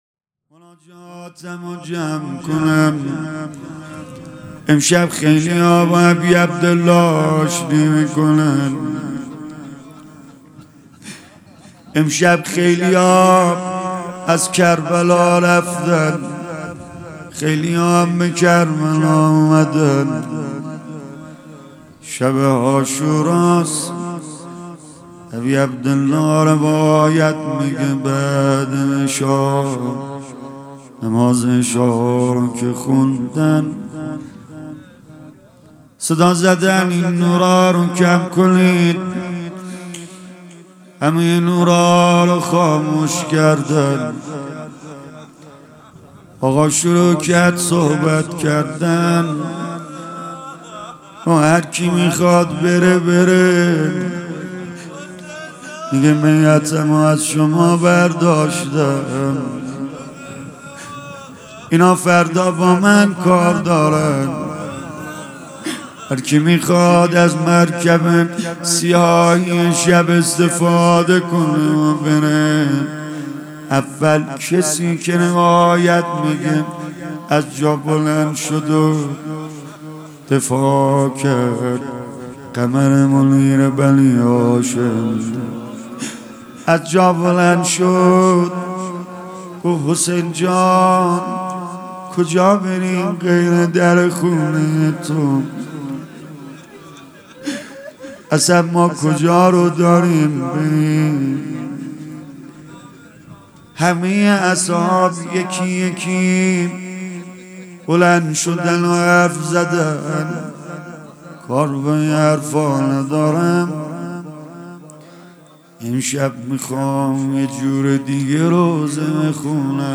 روضه | آه از آن ساعتی که با تن چاک چاک